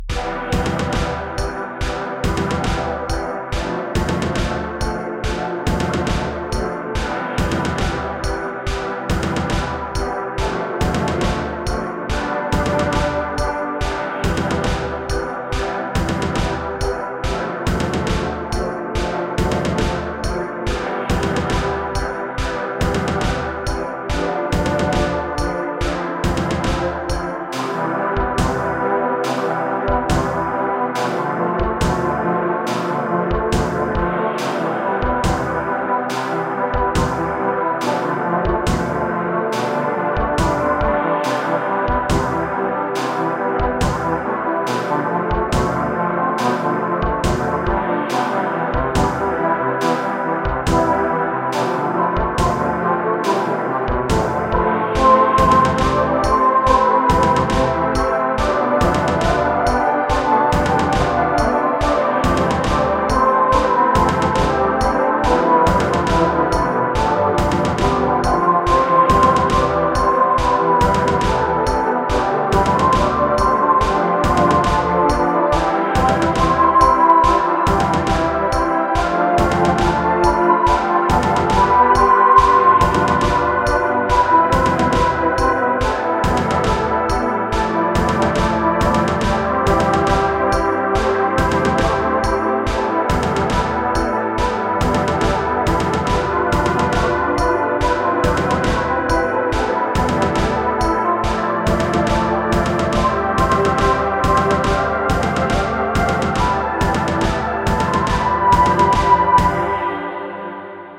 フリーBGM 陰鬱な世界